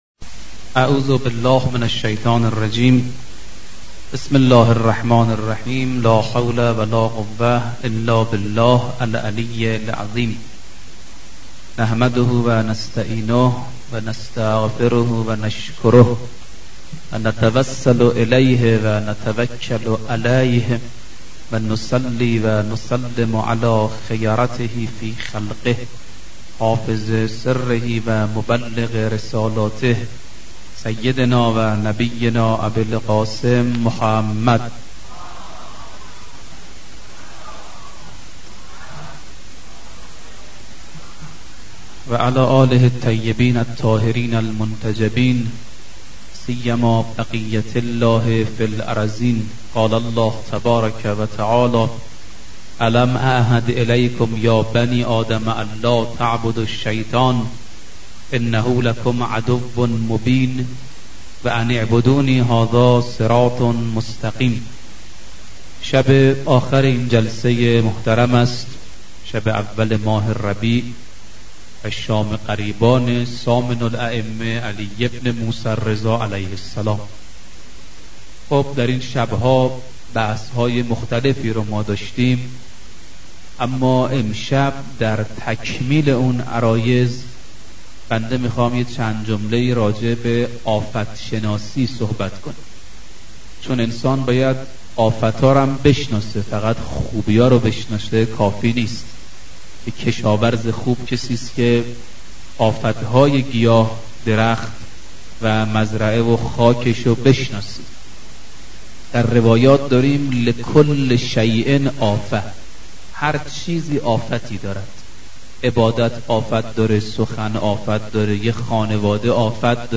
سخنرانی حجت الاسلام دکتر رفیعی موضوع : «آفت شناسی»